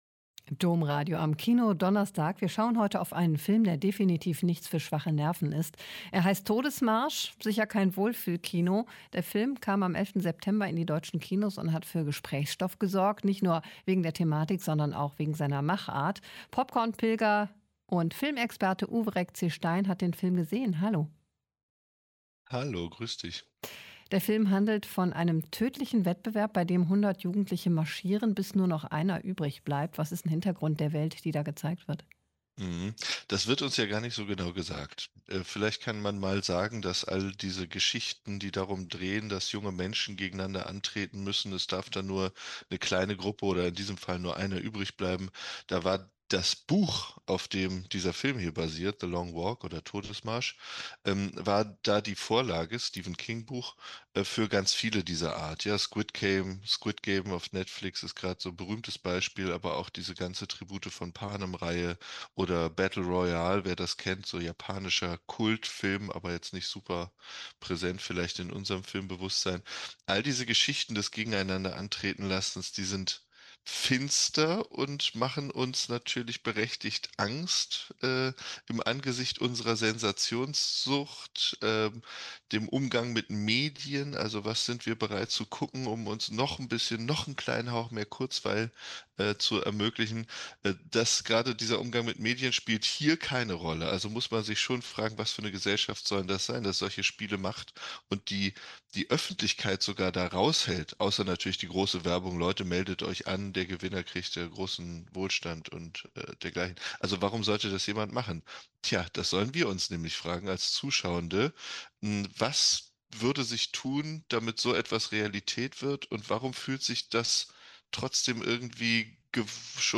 Ein Interview mit